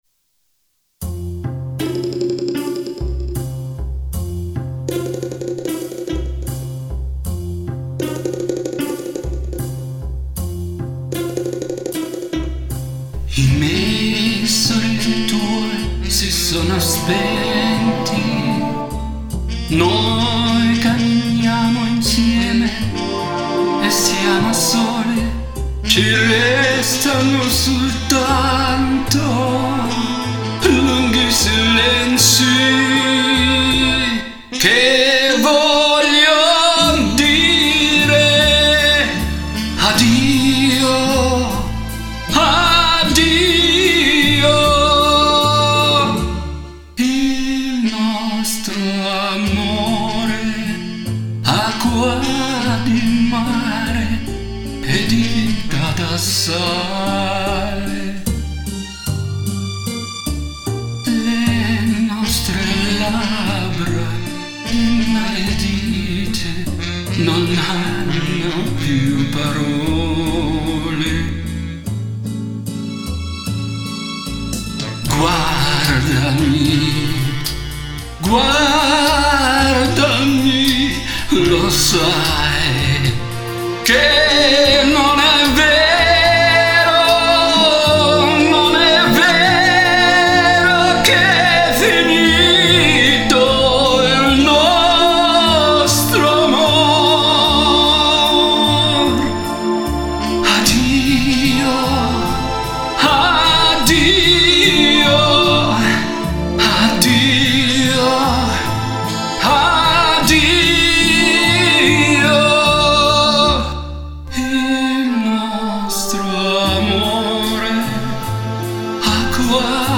отключить этот жуткий эффект канализационной трубы.